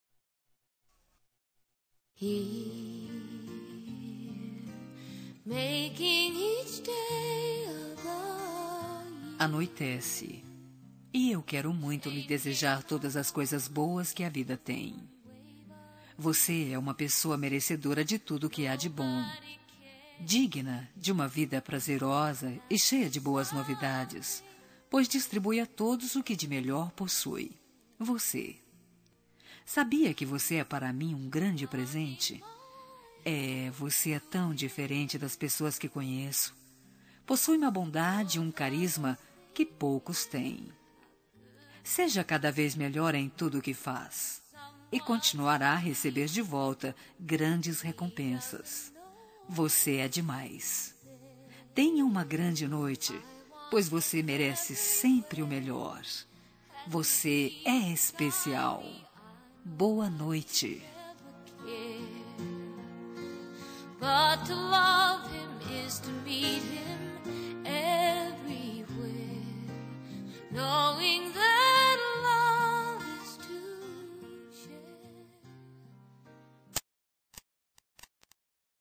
Telemensagem de Bom Dia – Voz Feminina – Cód: 6301 – Geral
6301-bom-dia-neutro-fem.mp3